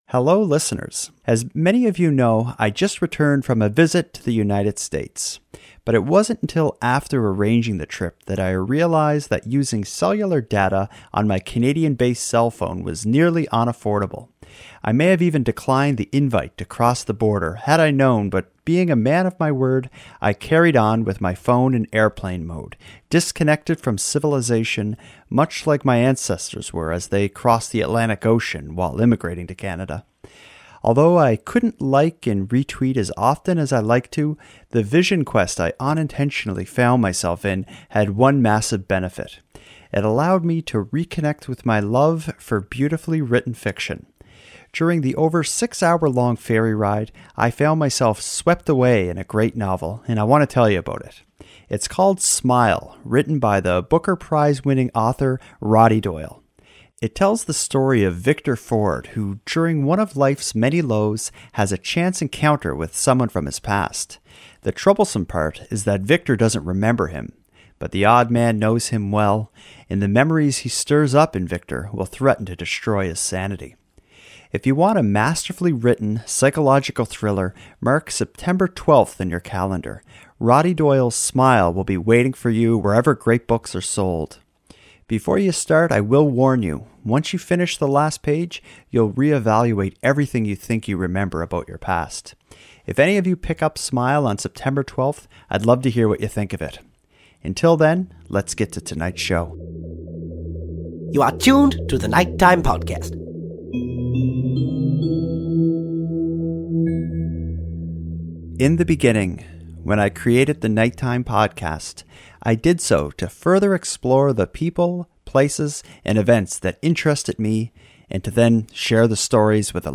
The event featured an interview with the controversial leader of The Satanic Temple, Lucien Greaves. In this episode I share some behind the scenes details, and present a recording of the great night I shared with many of the show's listeners.